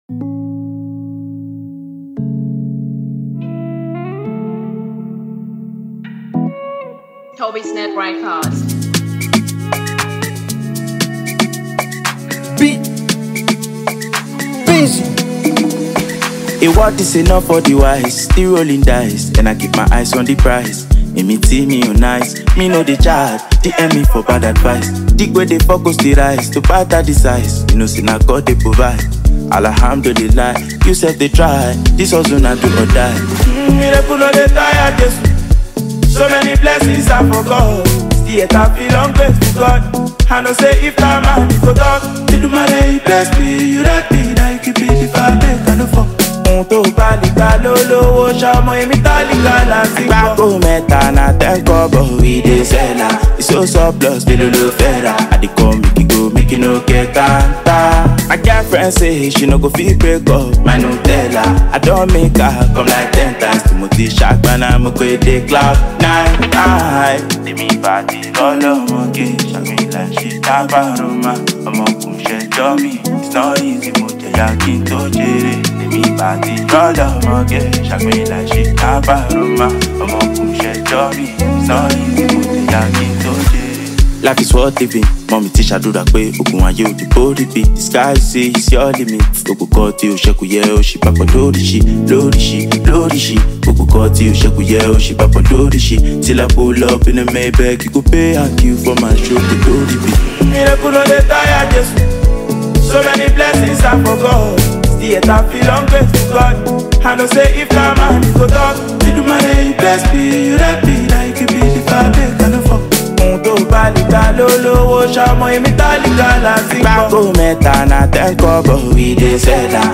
groovy new single